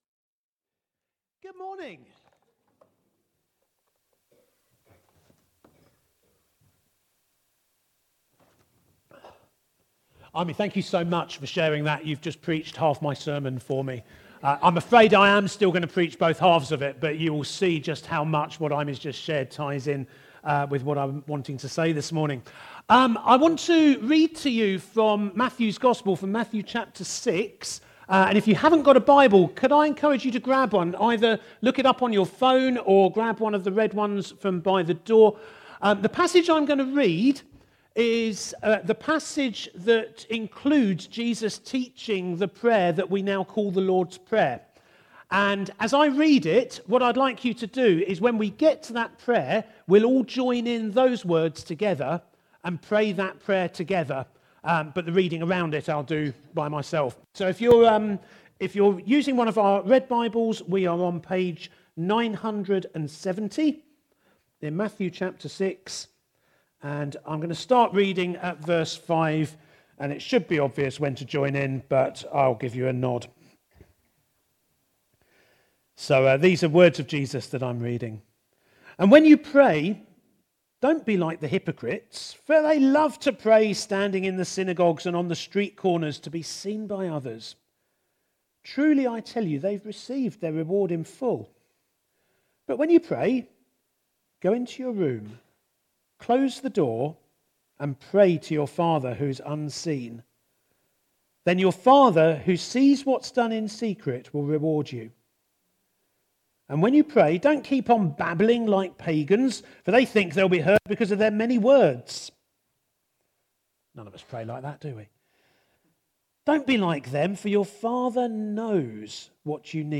A message from the series "Faith on the Frontline."